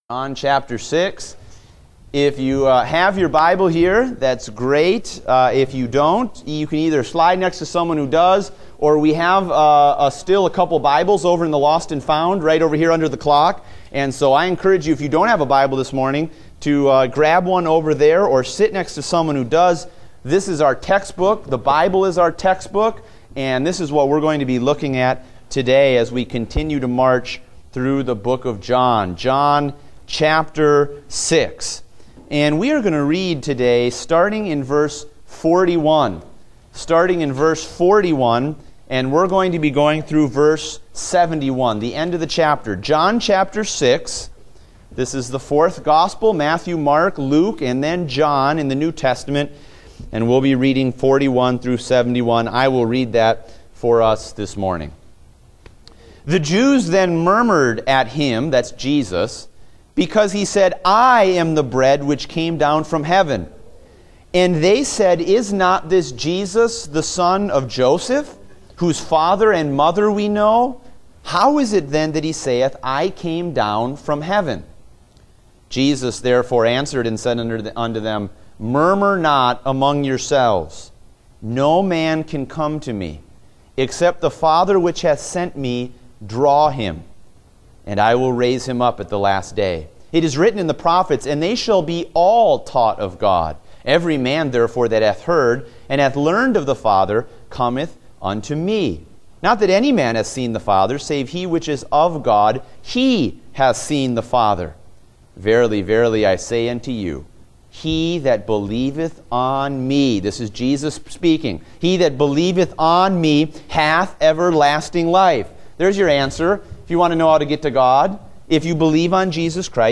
Date: August 21, 2016 (Adult Sunday School)